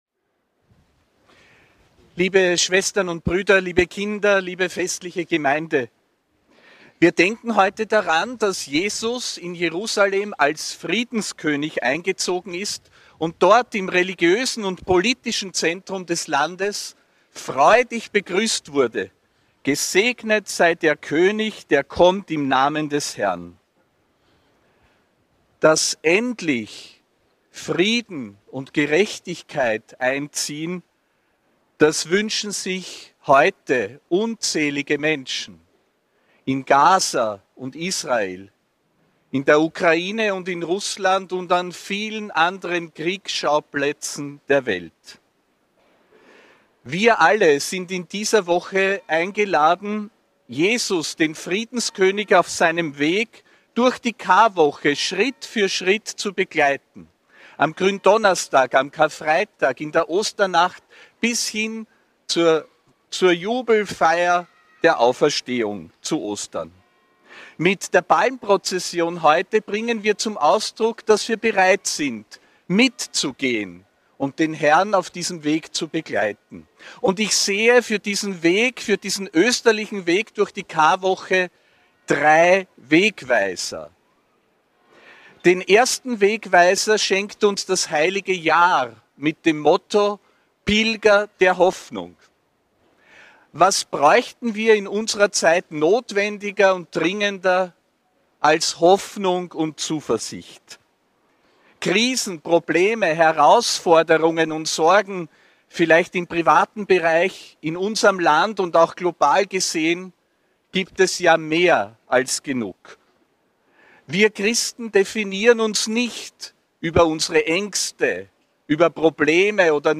Predigt zum Palmsonntag (13. April 2025)
Predigt des Apostolischen Administrators Josef Grünwidl bei der
Palmweihe am Graben in Wien, am 13. April 2025.